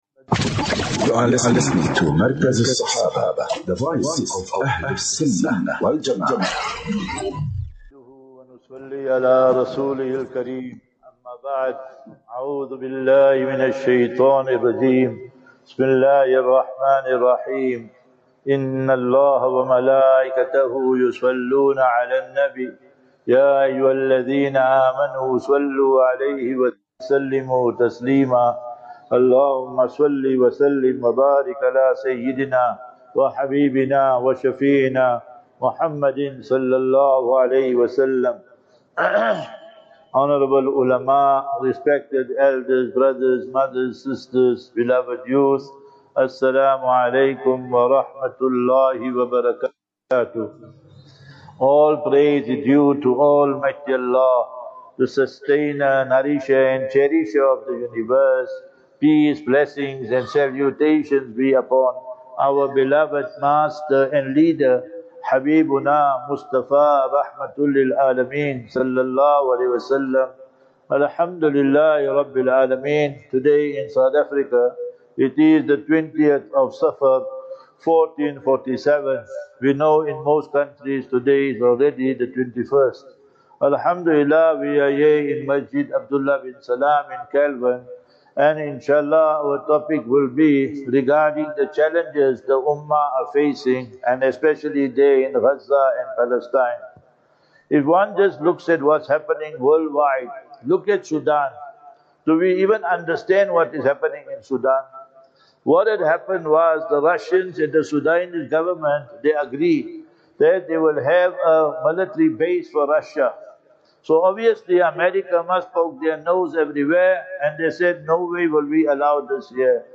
15 Aug 15 August 2025 - Jumu'ah Lecture at Abdullah bin Salaam Masjid (Kelvin).
Jum'uah Lecture